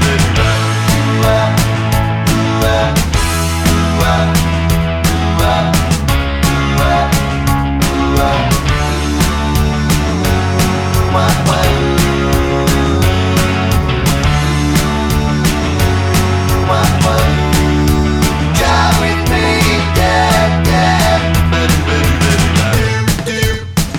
No High Vocal Pop (1970s) 3:15 Buy £1.50